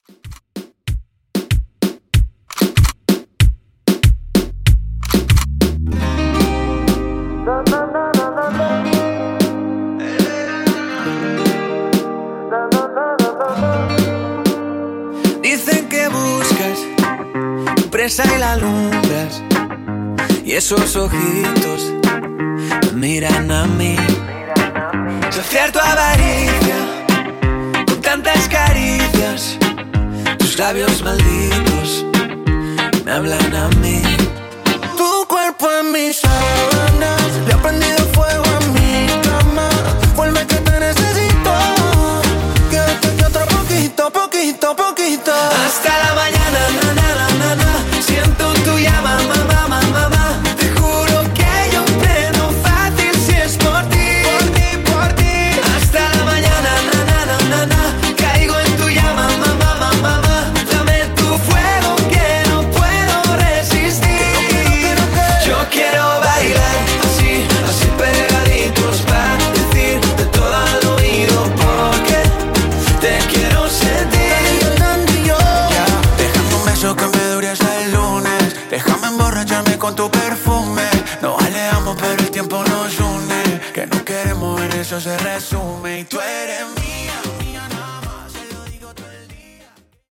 Urbano Reton)Date Added